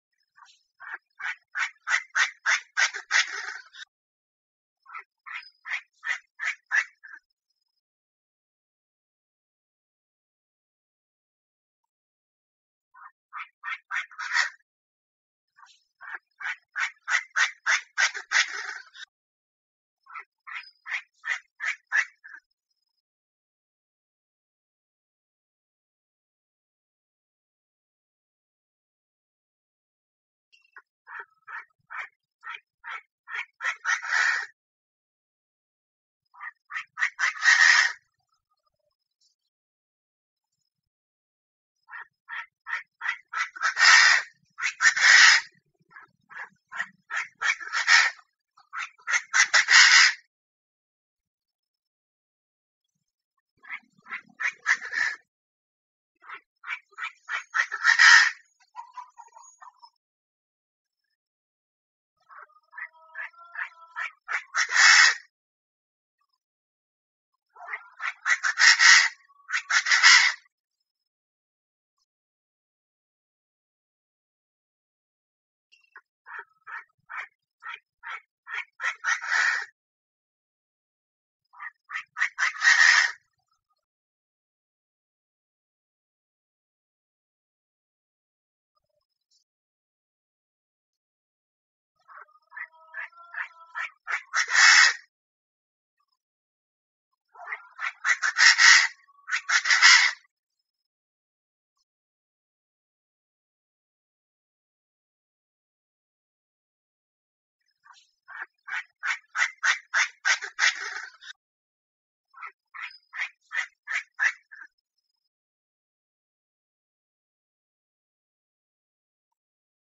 เสียงนกกระทาตัวเมียหมานๆ
เสียงนกกวักต่อกลางวัน เสียงนกกระทา mp3
หมวดหมู่: เสียงนก
คำอธิบาย: เสียงนกกระทาตัวเมียหมานๆ ตัดเสียงรบกวน 100% mp3
tieng-chim-da-da-mai-th-www_tiengdong_com.mp3